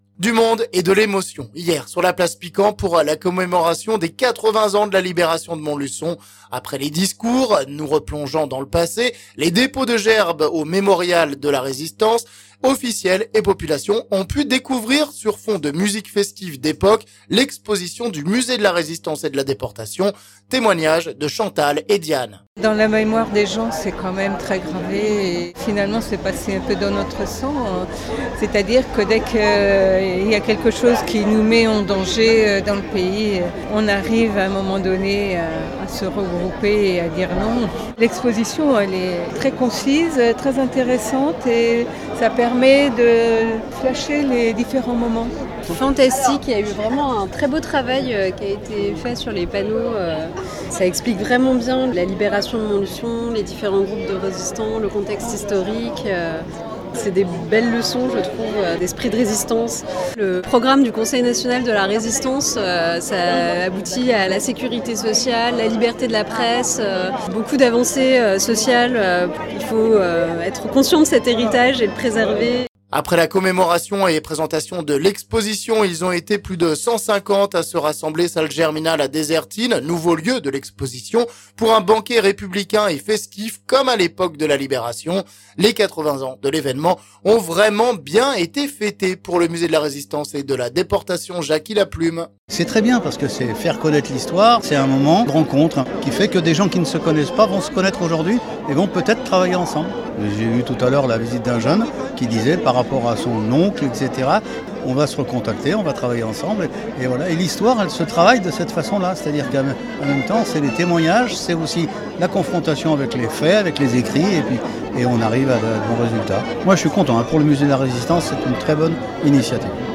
Beaucoup de monde et d’émotion hier sur la place Piquand à l’occasion de la commémoration des 80 ans de la libération de Montluçon.